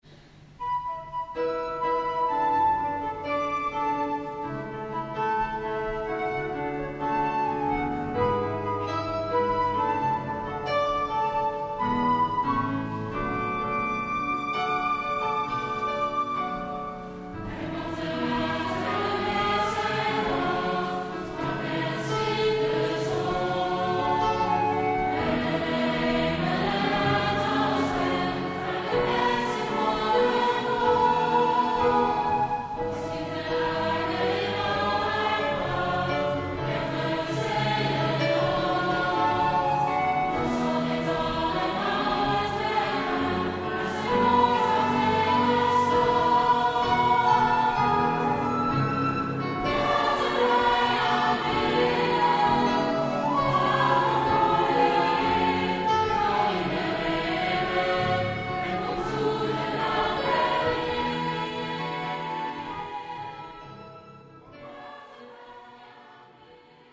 Opnames Kerstconcert 2004